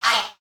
hit_02.ogg